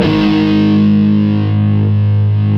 Index of /90_sSampleCDs/Roland LCDP02 Guitar and Bass/GTR_Distorted 1/GTR_Power Chords
GTR PWRCHR01.wav